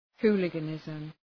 {‘hu:lıgə,nızm}
hooliganism.mp3